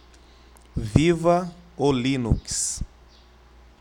O primeiro contém a frase “Viva o Linux” gerada com um simples microfone no programa record em modo consola.